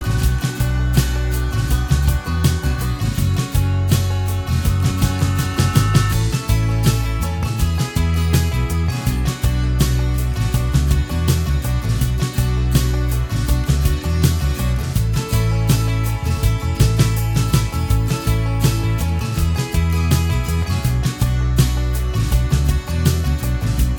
Minus All Guitars Pop (1990s) 3:49 Buy £1.50